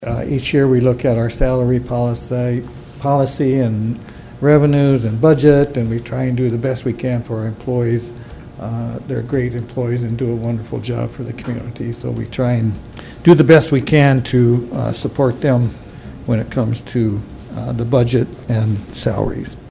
Harding says the city does what it can to show appreciation to its employees…